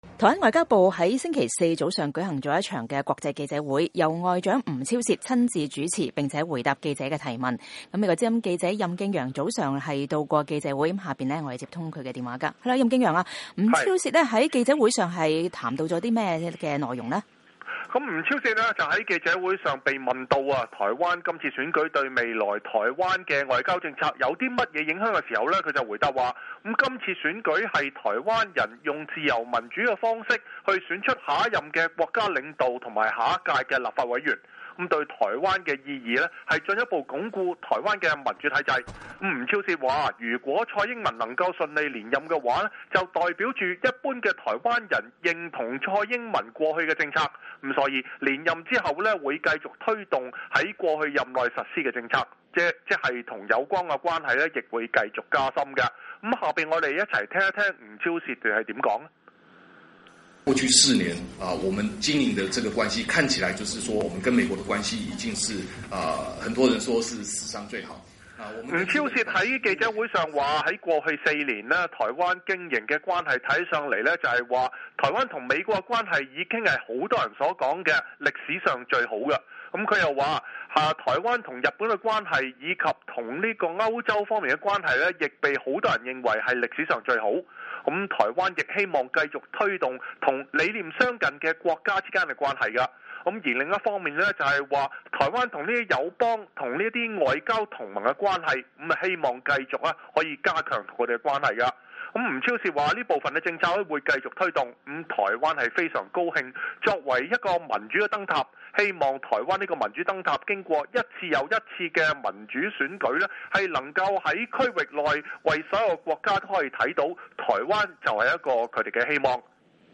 台灣外交部在星期四早上舉行了一場國際記者會，由外長吳釗燮親自主持並回答記者提問。吳釗燮說，這次選舉是台灣人用自由民主的方式去選出下一任的國家領導和下一屆的立法委員。